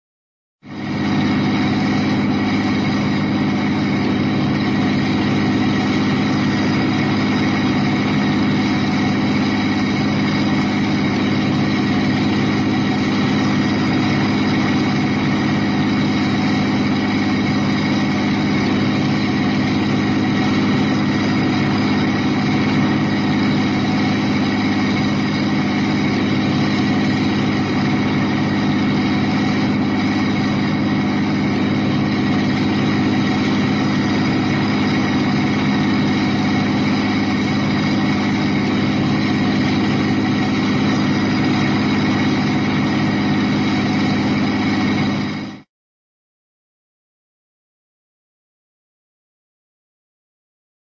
BigTrolleyDrive.mp3